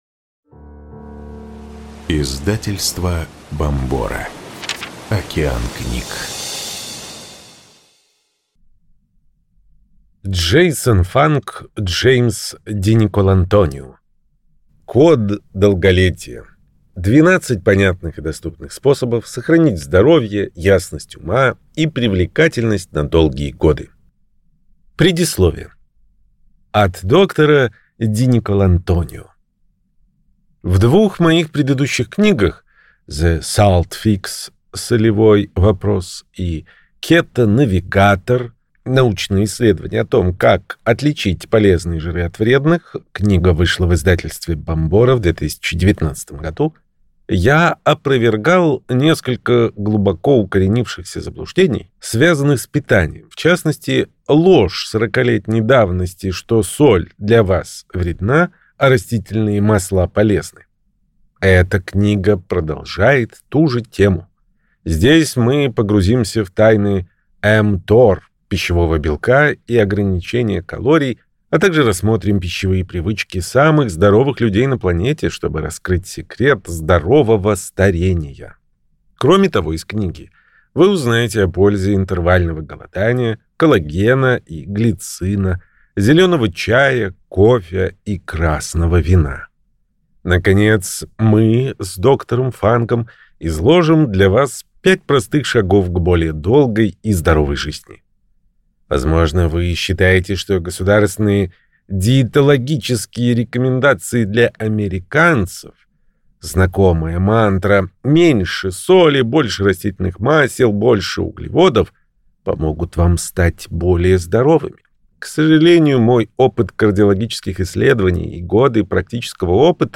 Аудиокнига Код долголетия. 12 понятных и доступных способов сохранить здоровье, ясность ума и привлекательность на долгие годы | Библиотека аудиокниг